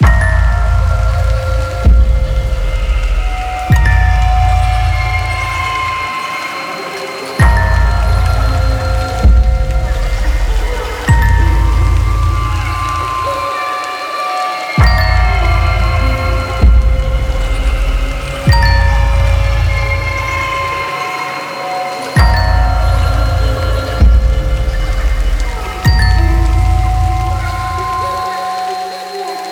HORROR MUSIC BOX / Loop